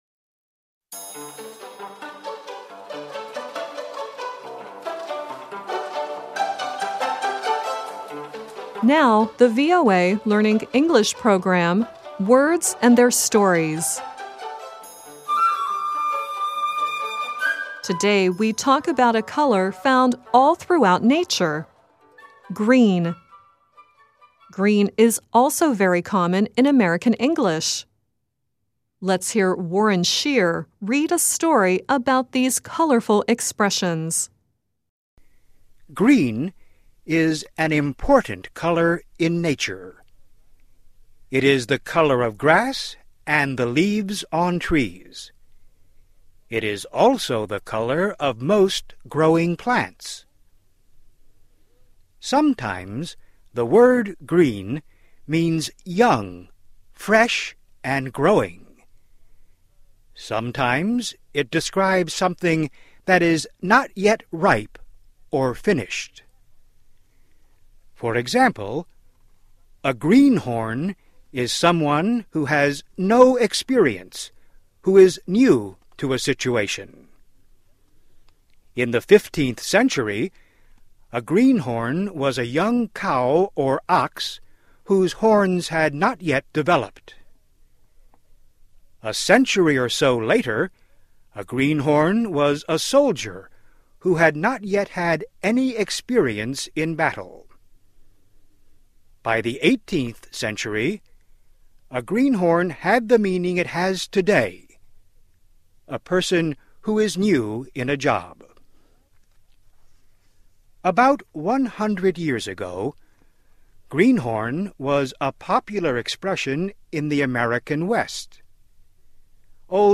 And the end of the show is John Legend singing “Green Light.”